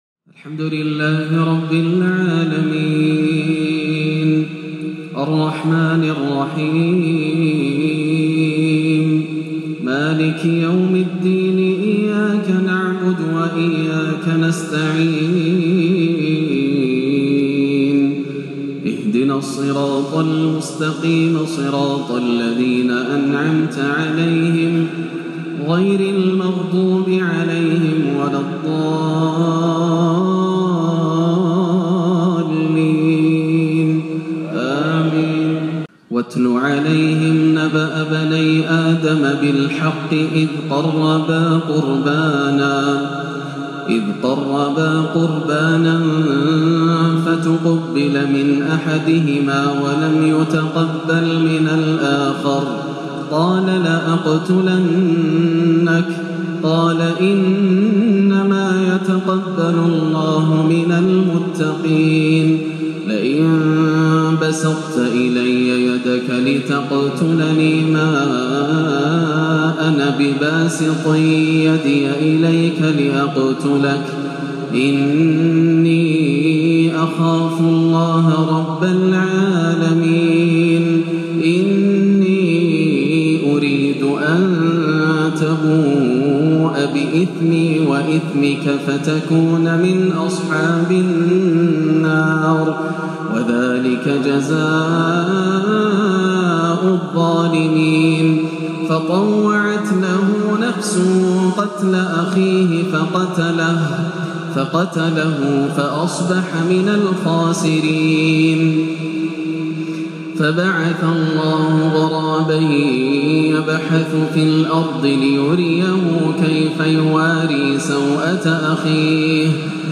قصة ابني آدم ~ تلاوة كردية حزينه لما تيسر من سورة المائدة - السبت 23-12 > عام 1437 > الفروض - تلاوات ياسر الدوسري